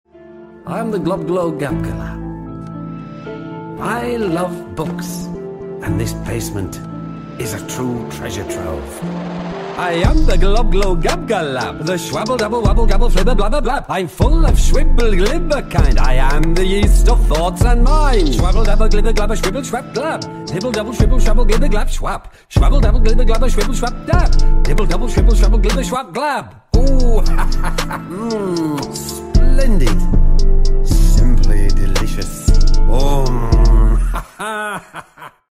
Рэп Хип-Хоп Рингтоны